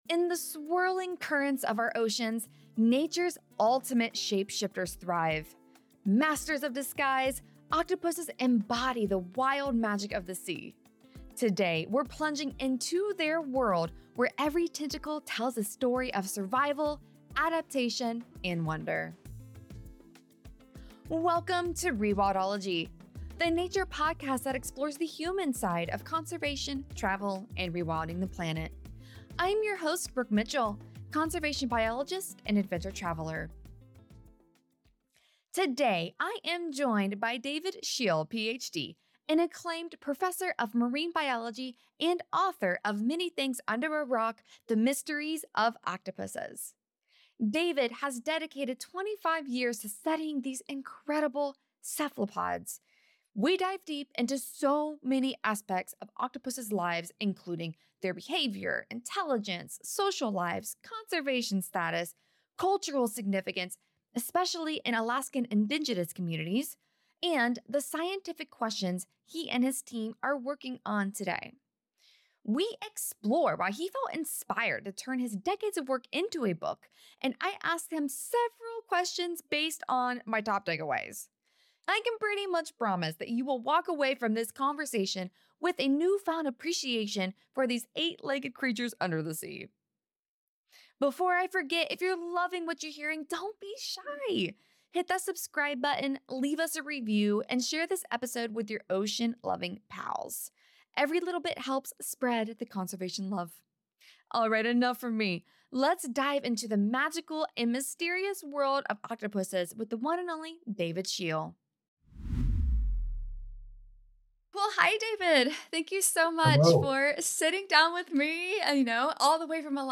Don't miss this intriguing conversation and remember to subscribe, leave a review, and share with fellow ocean lovers!00:00 Introduction to the Mystical World o